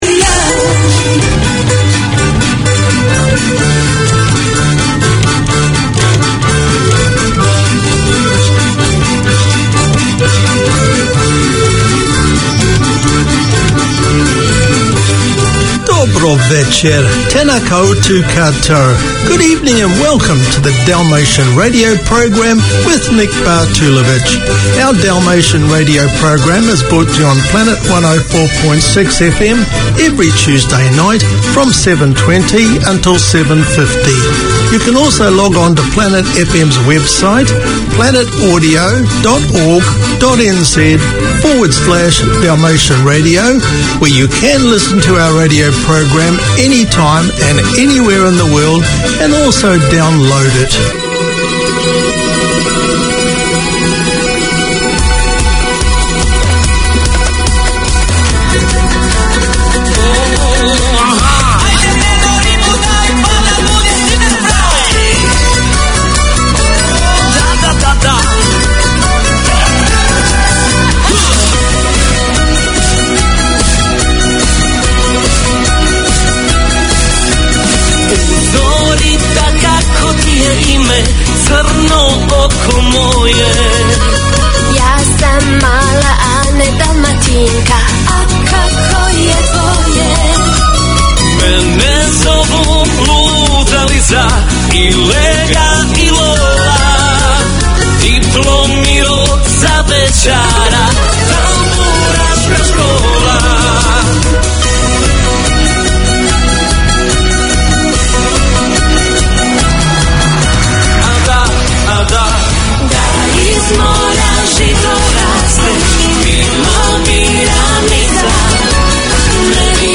We present Society news and explore the achievements of Kiwis of Dalmatian descent. The music selected from around the former Yugoslavia is both nostalgic and modern.